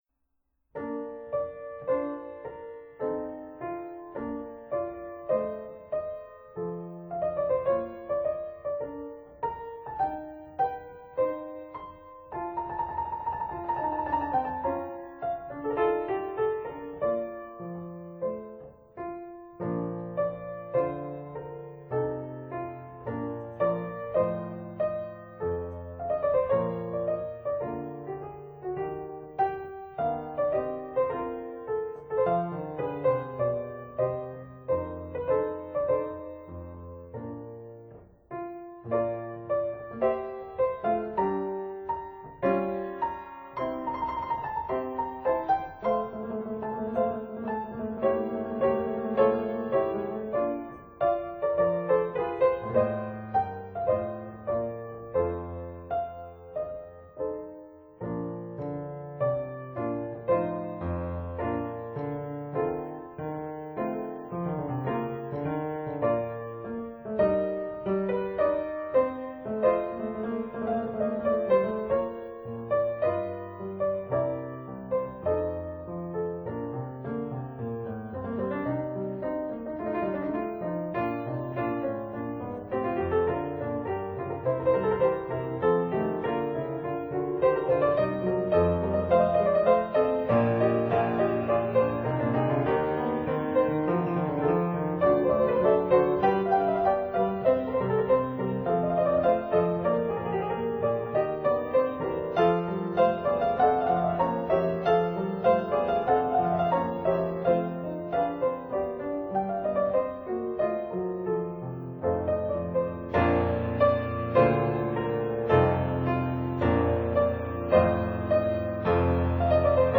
1858 Pleyel Fortepiano
(Period Instruments)